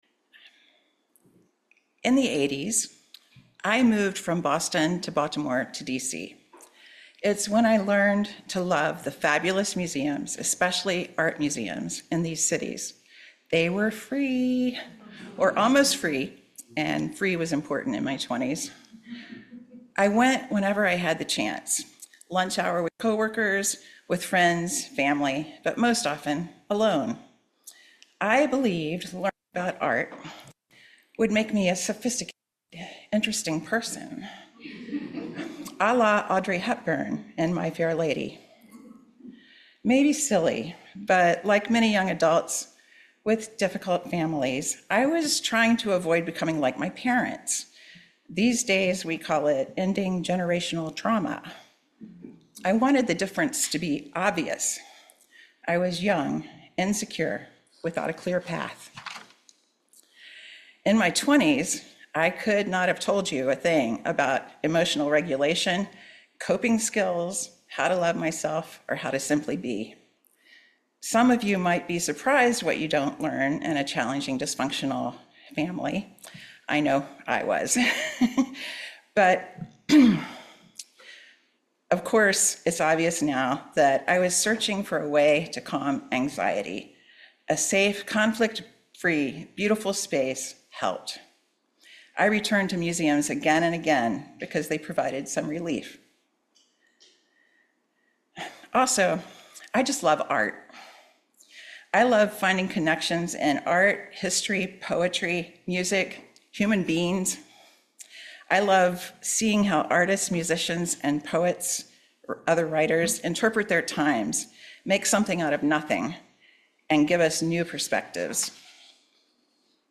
In this reflective sermon, the speaker examines the profound value of cultivating presence in a chaotic, fast-paced world